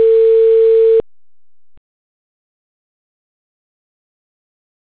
ring_back.wav